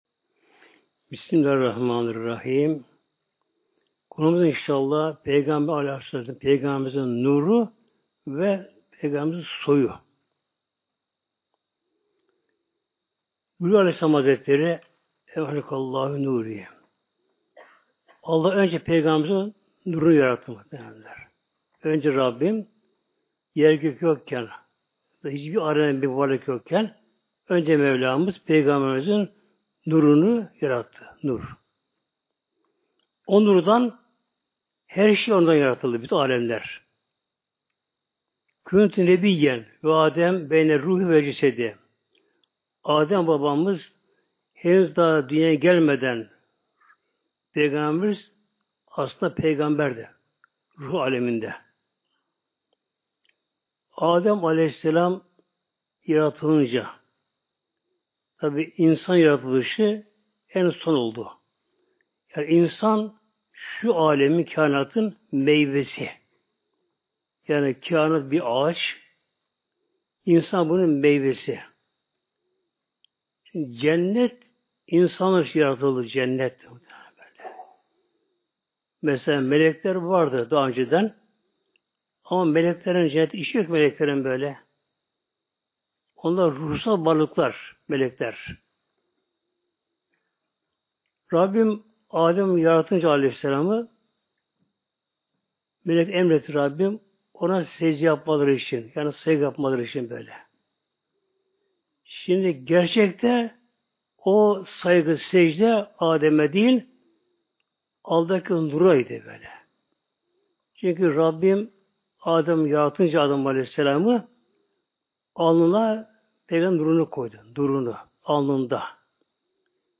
Sohbet